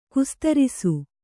♪ kustarisu